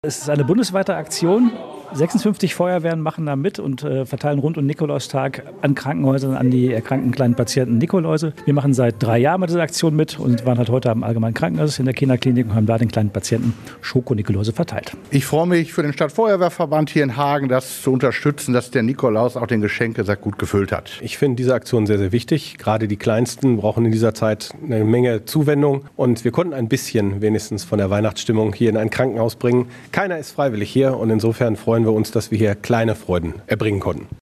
Feuerwehrmänner zu der Aktion